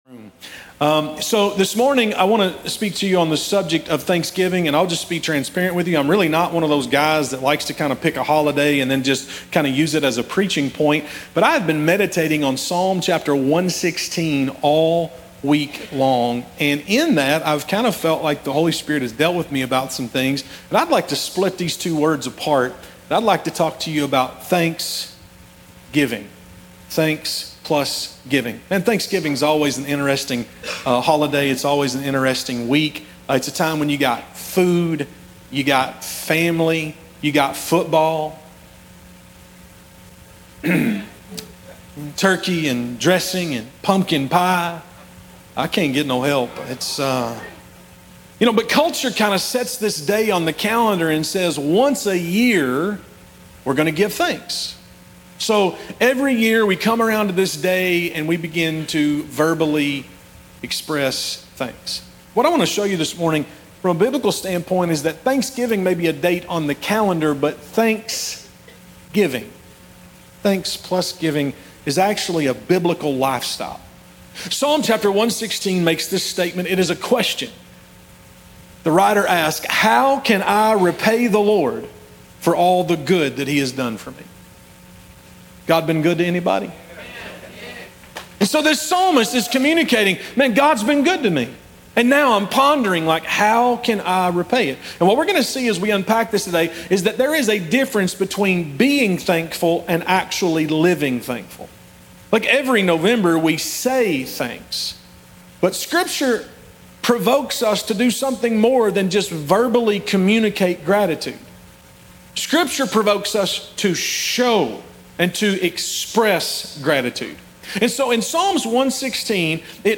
In this message from Psalm 116, we explore the difference between feeling thankful and truly living thankful. Discover how biblical gratitude returns to God, expresses generosity, and transforms lives through thanks living, not just thanks saying.